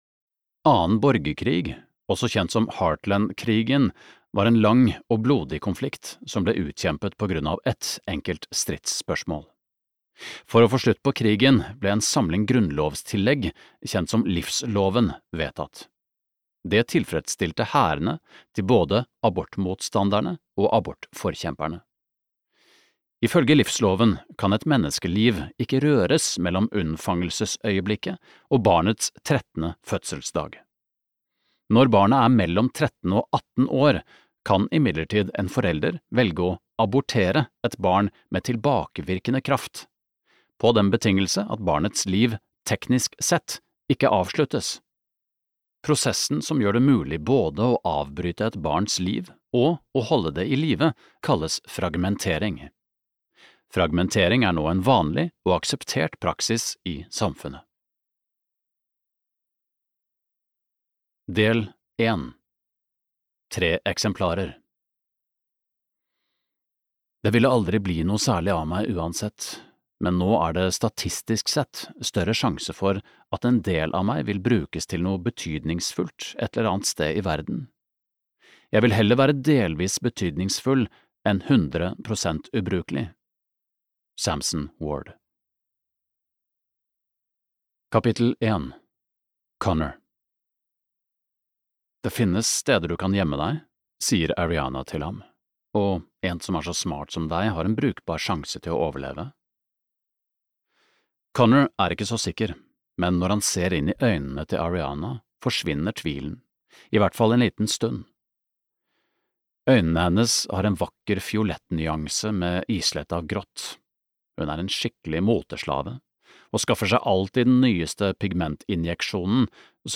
Splintret (lydbok) av Neal Shusterman